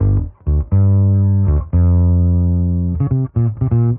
Index of /musicradar/dusty-funk-samples/Bass/120bpm
DF_PegBass_120-G.wav